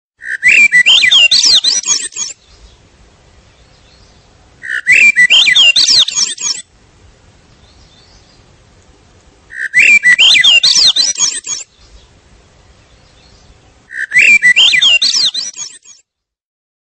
Toque Swainsons Thrush
Categoria Efeitos Sonoros
Swainsons-Thrush.mp3